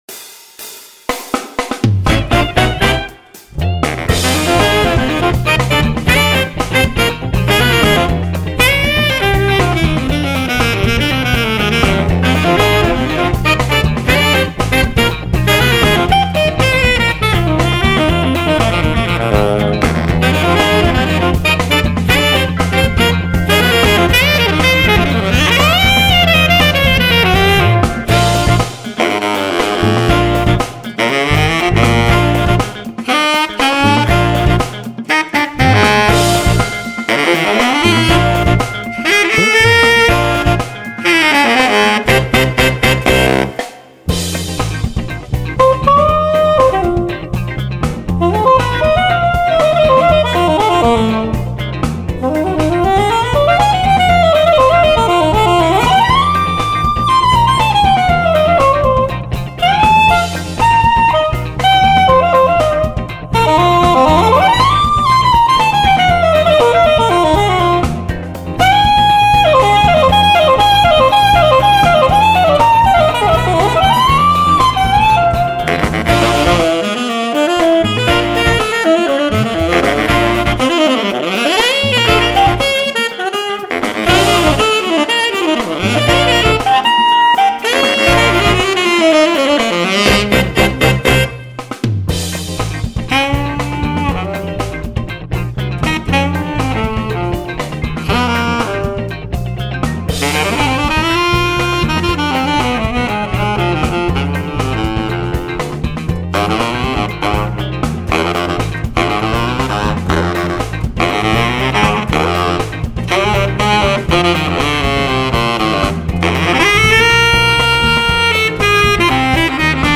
音色と特徴ダークで音が太い、輪郭のある音。